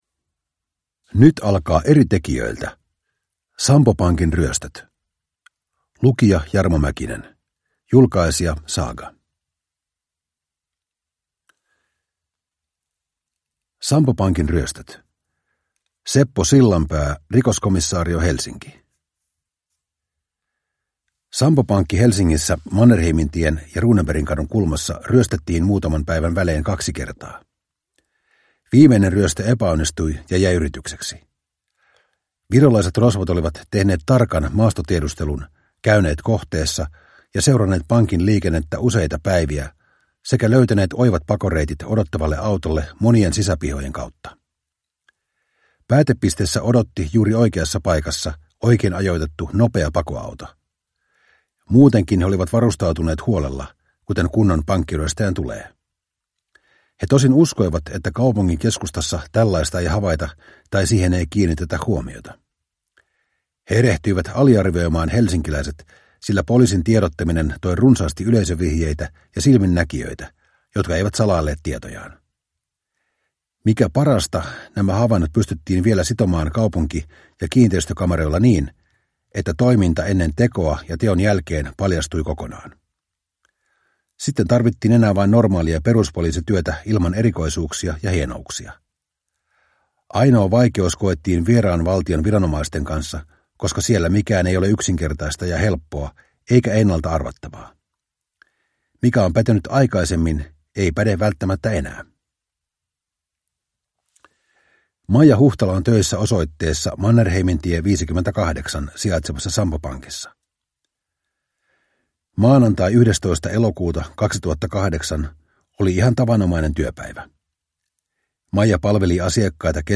Sampo Pankin ryöstöt (ljudbok) av Eri tekijöitä